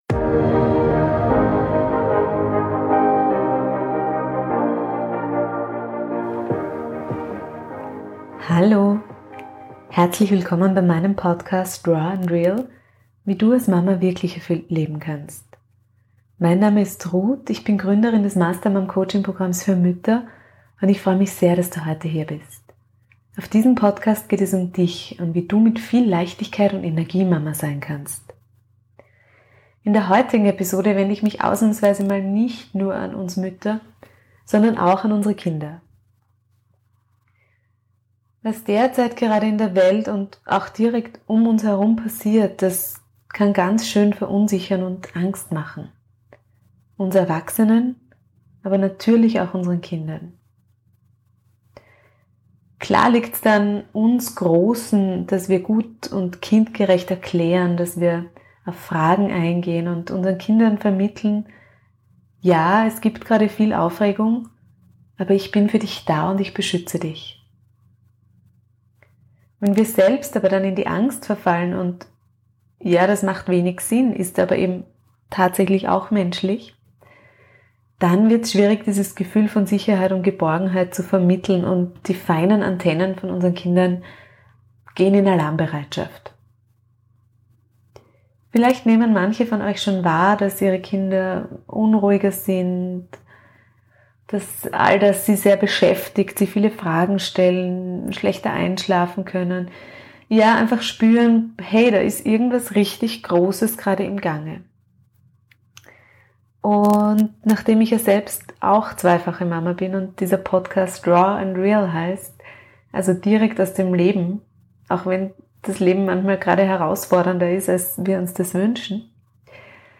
Deshalb gibt es heute eine Meditation für unsere Kinder!